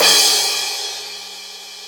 CM2 CRASH  5.wav